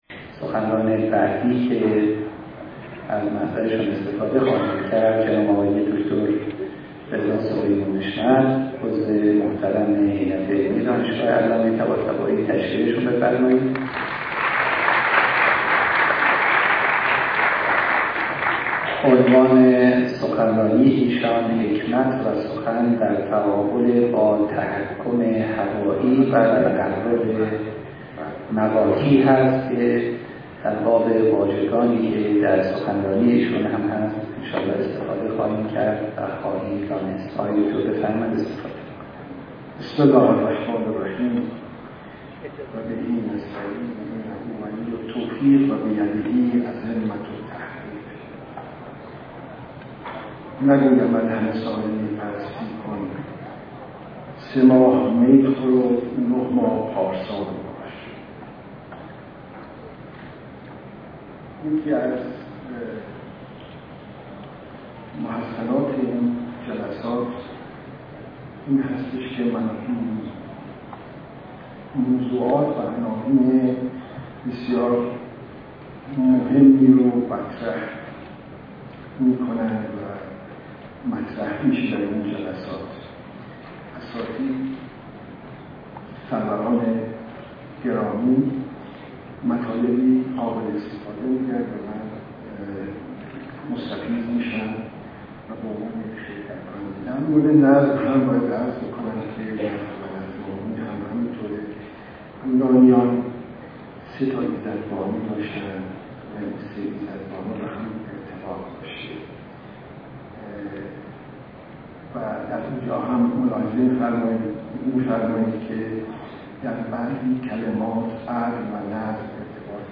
این همایش به همت بنیاد حکمت اسلامی صدرا ۳۱ اردیبهشت ماه ۹۴ در این مرکز برگزار شد.